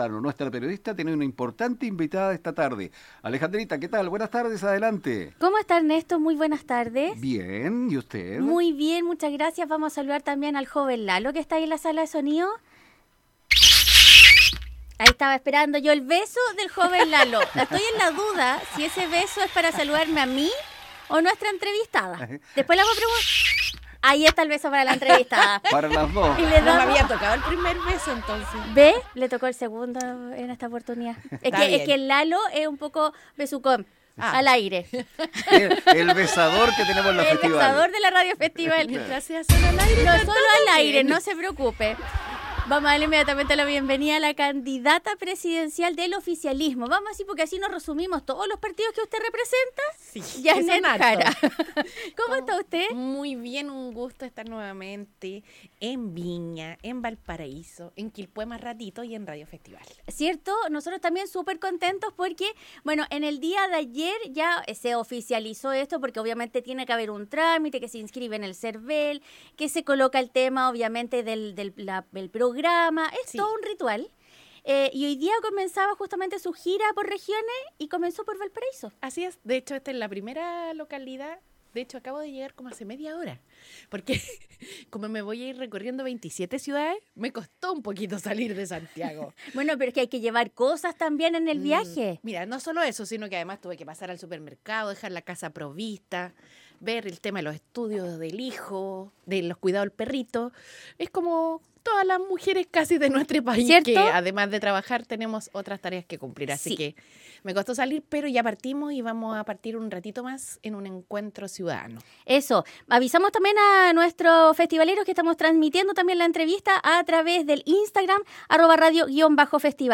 En los estudios de Radio Festival estuvo la candidata Presidencial Jeannette Jara para entregar detalles de su programa, así como algunos planes para la Región. Esto en el inicio de su gira en la Región de Valparaíso.